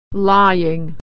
Vowel Sounds /e, ey/ - Practice - Lie-lay - Authentic American Pronunciation
lying /ay/